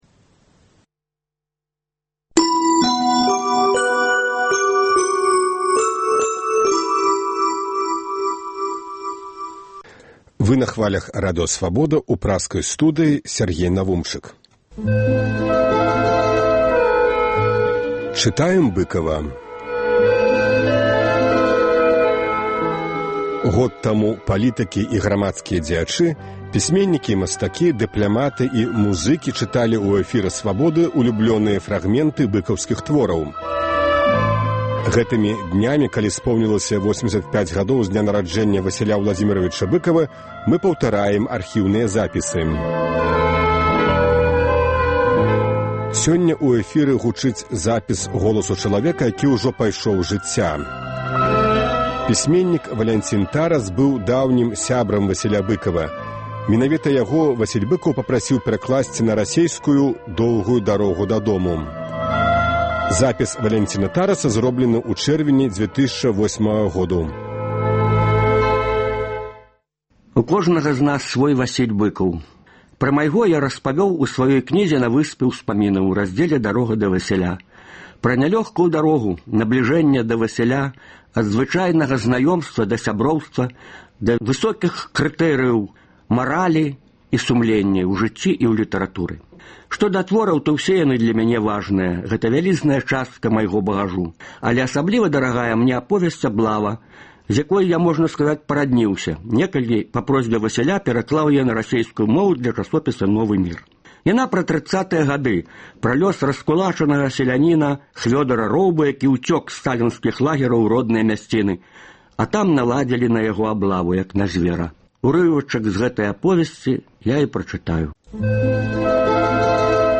Вядомыя людзі Беларусі чытаюць свае ўлюбёныя творы Васіля Быкава. Сёньня гучыць запіс голасу чалавека, які ўжо пайшоў з жыцьця. Пісьменьнік Валянцін Тарас быў даўнім сябрам Васіля Быкава.